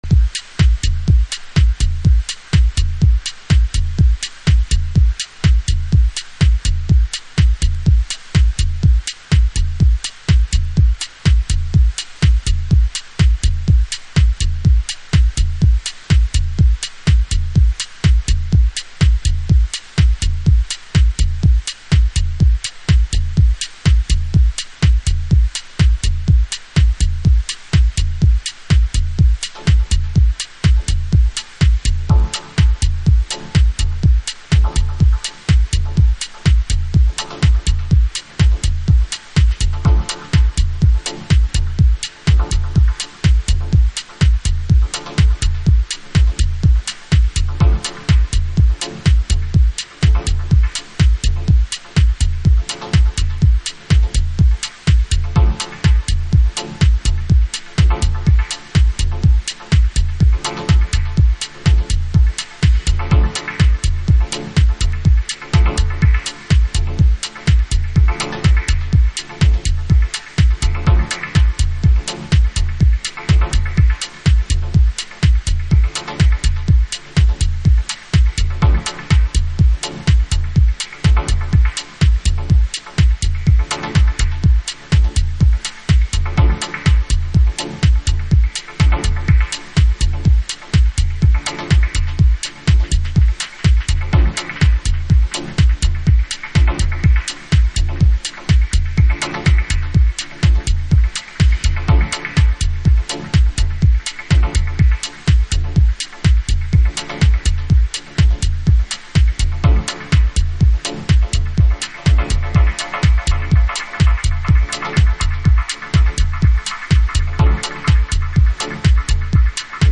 House / Techno
攻めのシンセウェーヴが心地良い。
経てきたダブテクノです。